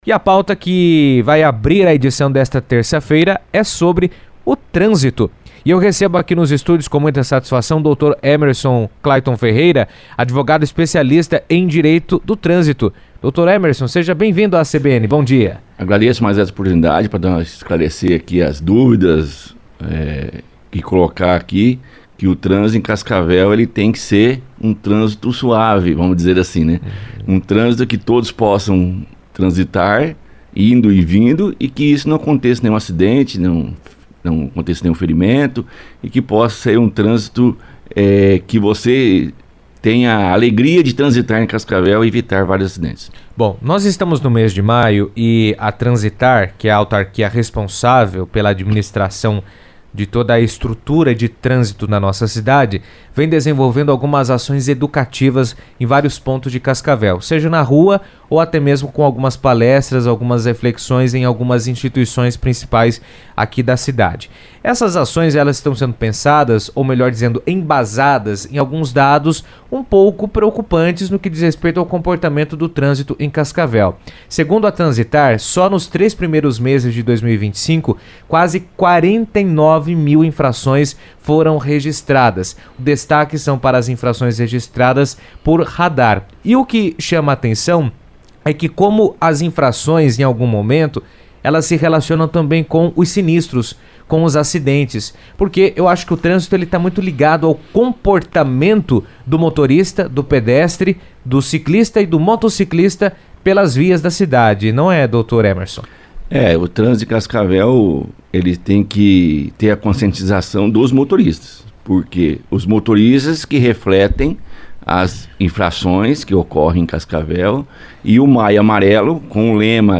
A conversa teve como base, os dados de infraçoes e acidentes registrados nos três primeiros meses de 2025, em Cascavel.